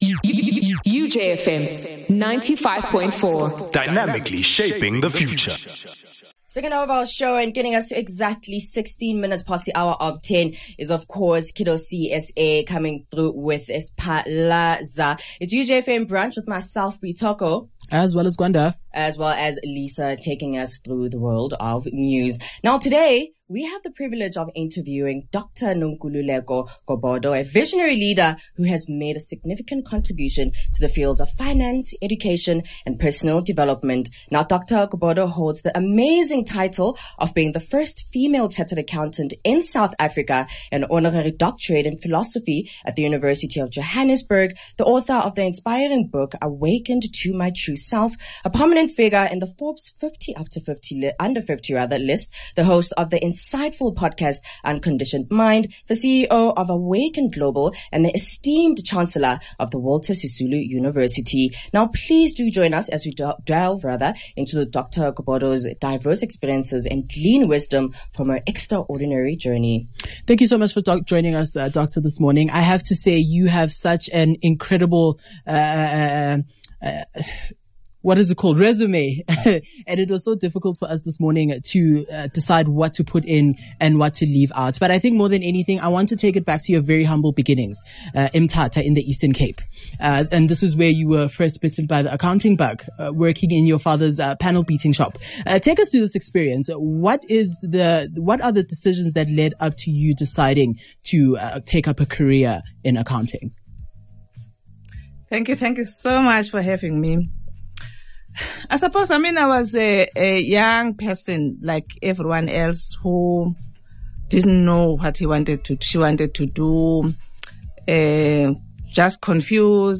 In a captivating interview on the UJFM Brunch show